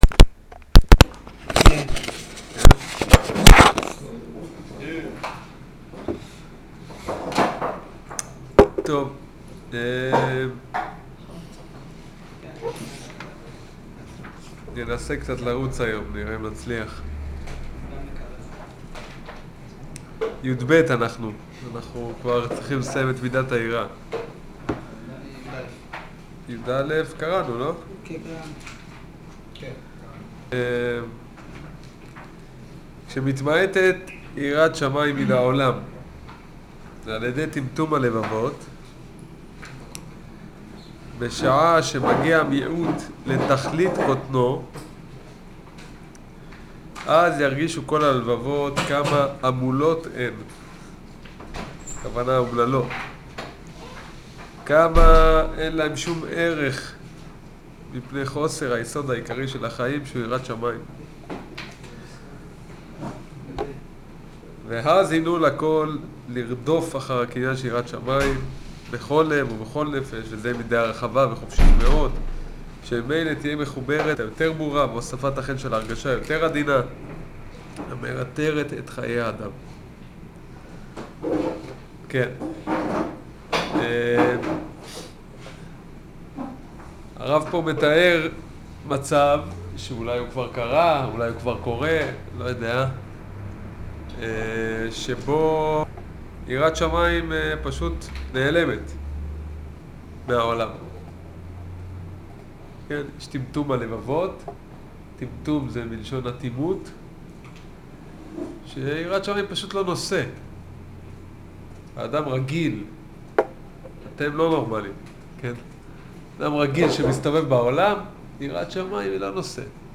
שיעור יראה י"ב